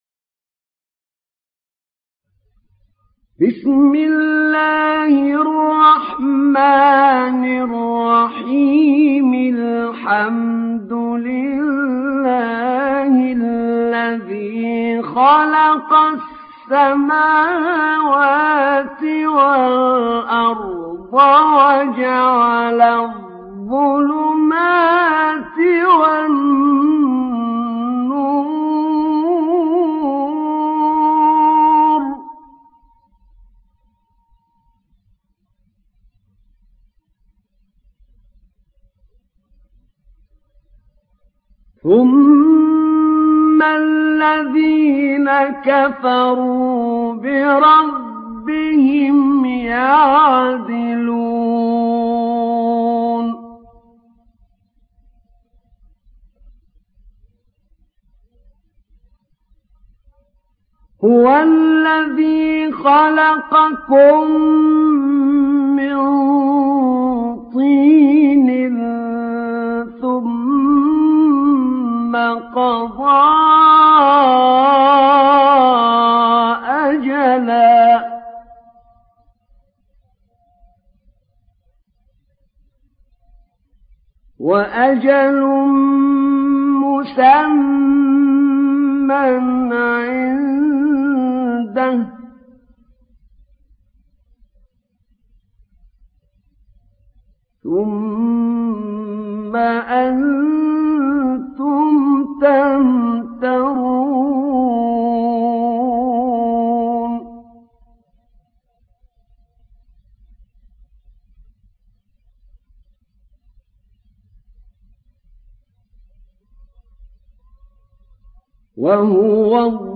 Enam Suresi İndir mp3 Ahmed Naina Riwayat Hafs an Asim, Kurani indirin ve mp3 tam doğrudan bağlantılar dinle